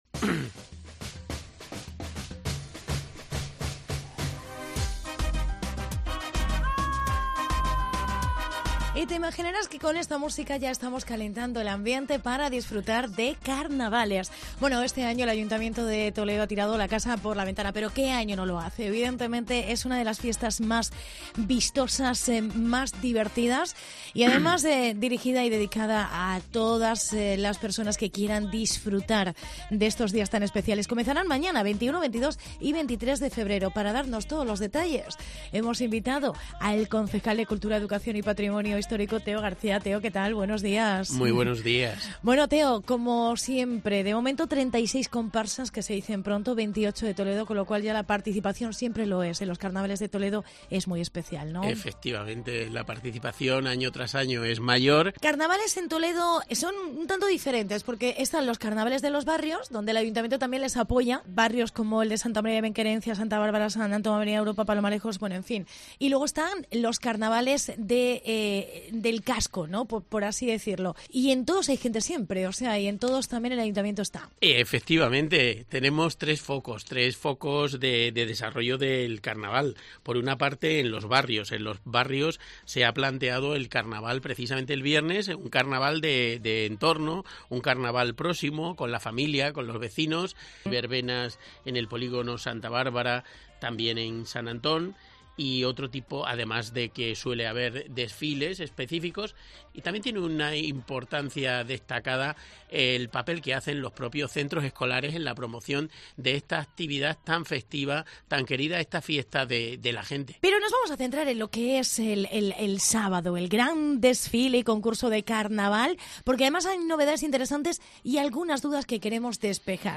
Entrevista Teo García: Concejal de Cultura, Educación y Patrimonio Histórico